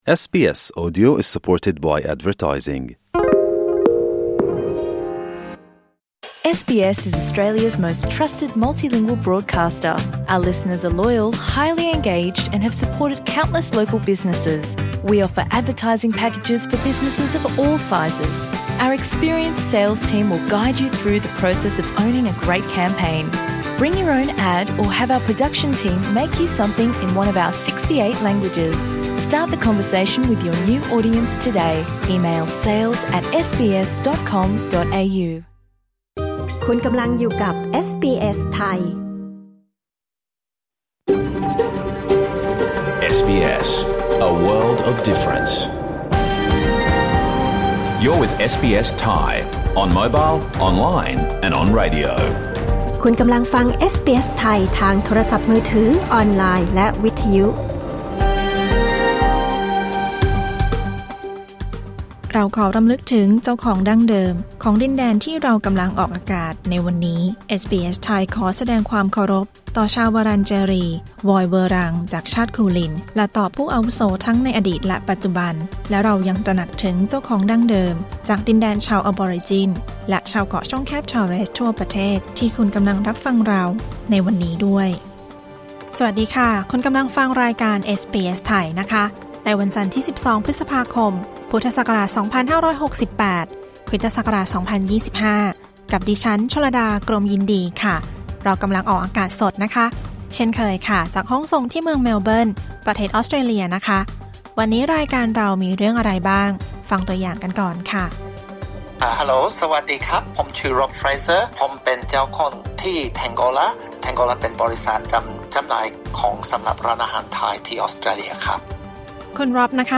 รายการสด 12 พฤษภาคม 2568